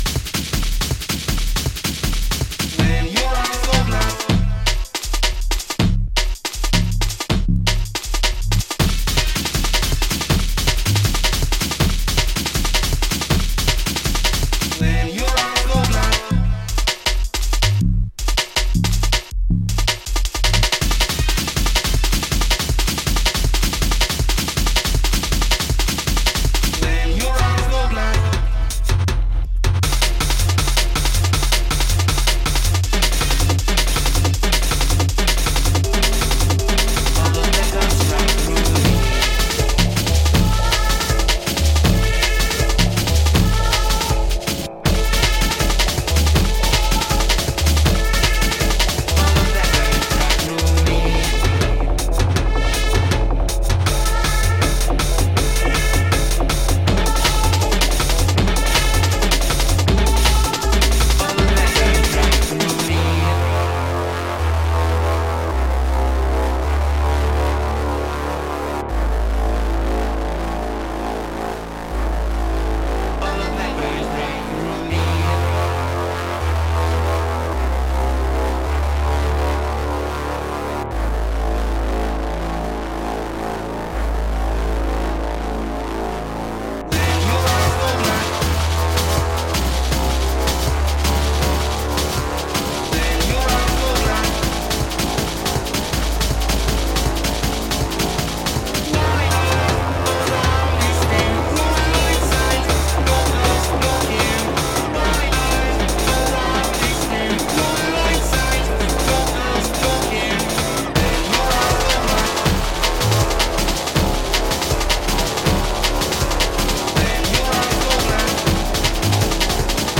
Começa com crackle
arpejos trance com elementos 16bit
batida certeira, bass-heavy